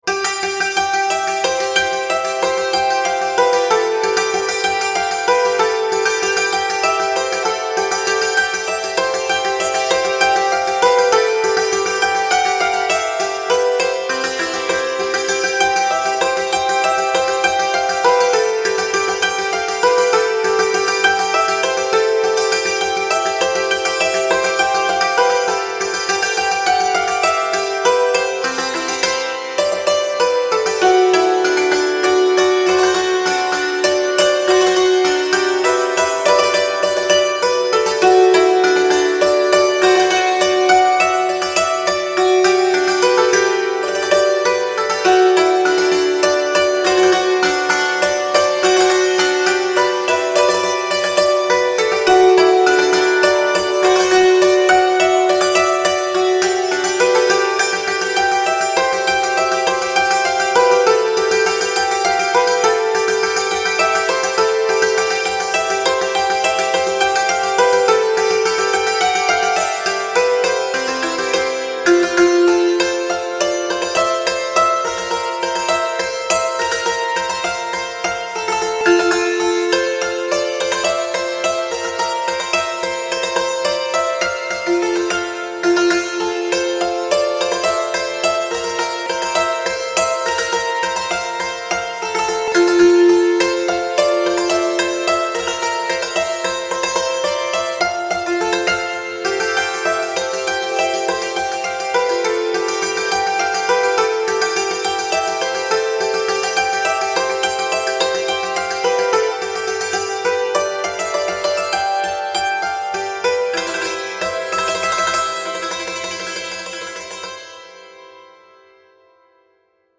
piano / bass